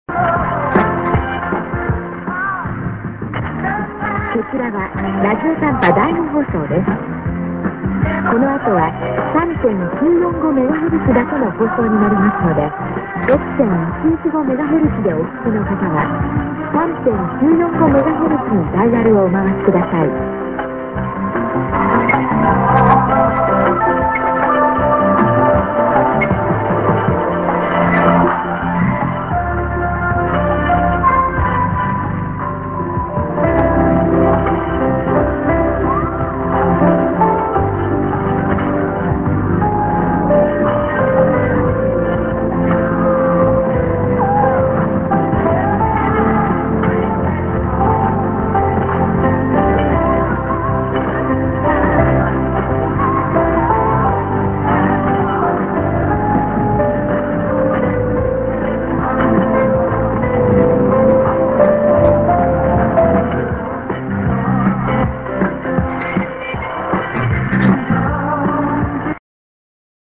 End ->ID(women)-> -->after 3945kHz